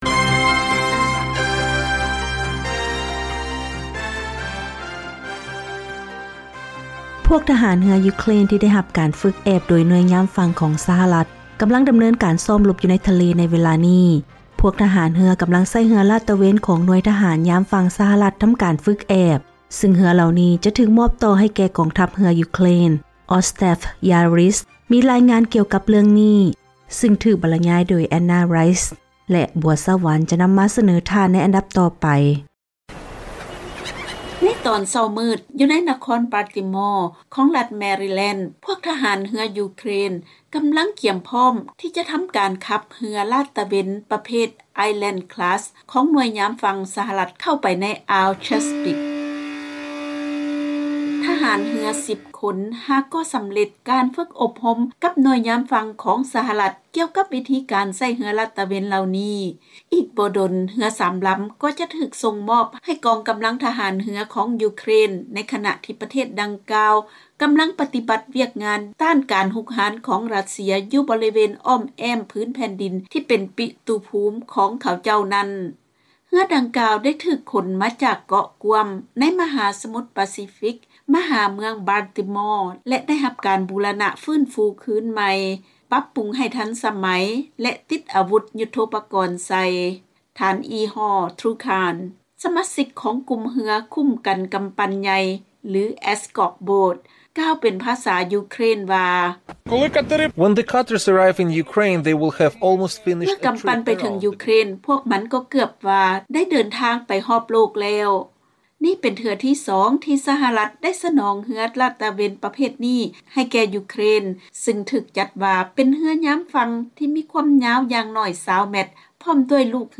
ເຊີນຟັງລາຍງານກ່ຽວກັບການຝຶກອົບຮົມທະຫານເຮືອຂອງຢູເຄຣນໂດຍໜ່ວຍຍາມຝັ່ງຂອງ ສຫລ